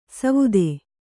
♪ savude